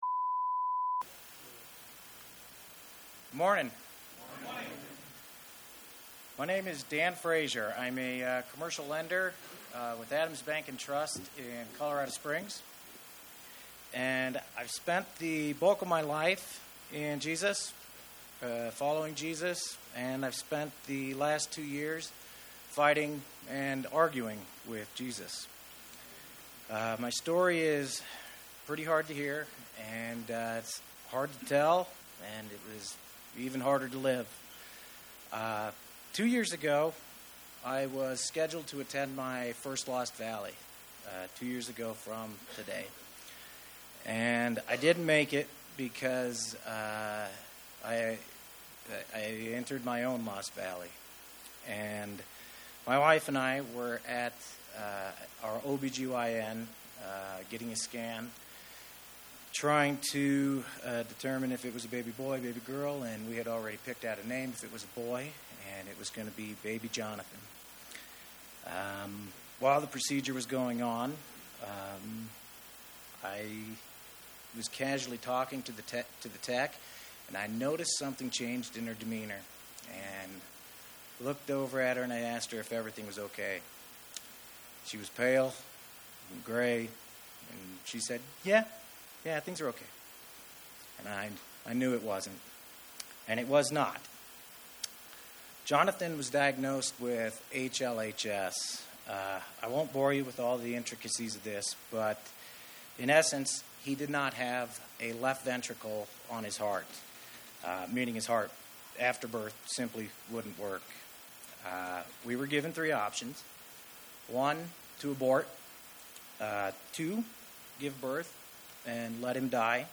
Dad of HLHS Warrior: Testimony at Lost Valley